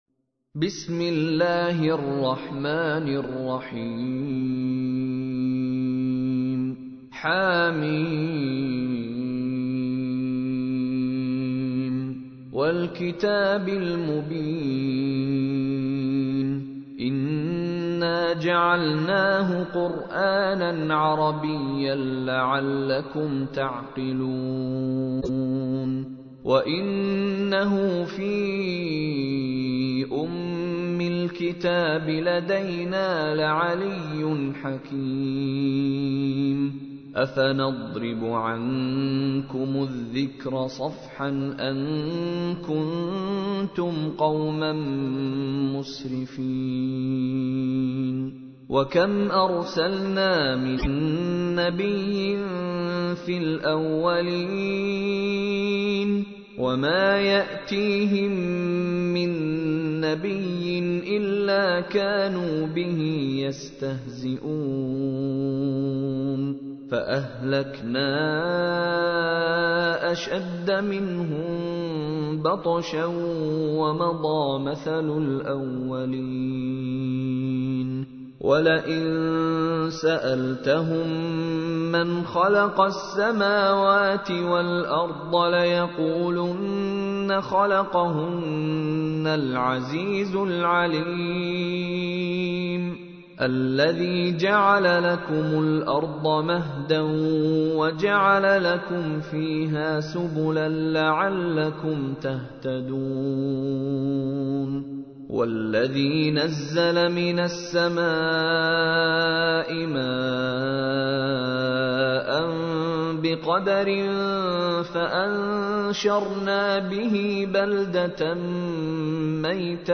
تحميل : 43. سورة الزخرف / القارئ مشاري راشد العفاسي / القرآن الكريم / موقع يا حسين